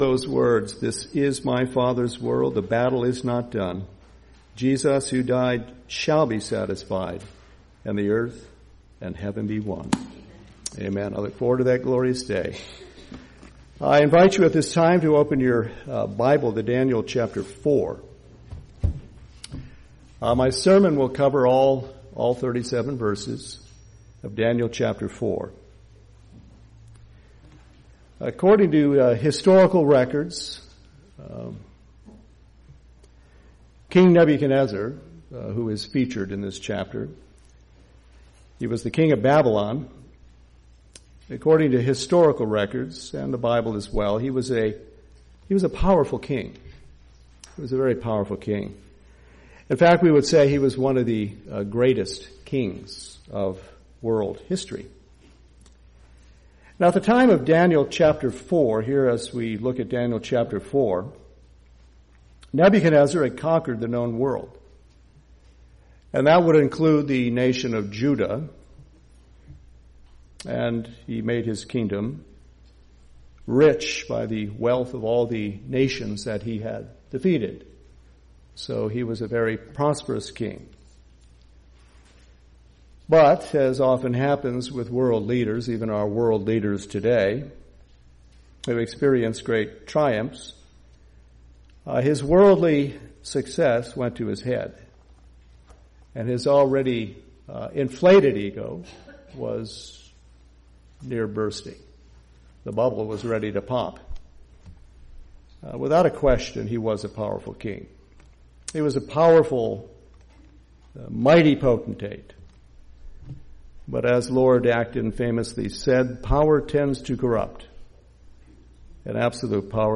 Sermons – Grace Presbyterian Church of Baton Rouge, LA